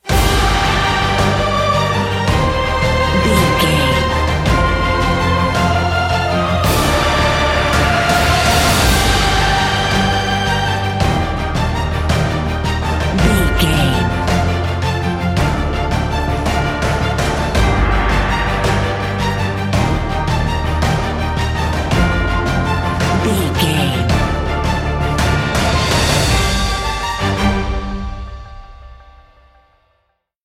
Epic / Action
Uplifting
Aeolian/Minor
epic
powerful
brass
cello
choir
drums
strings
synthesizers
hybrid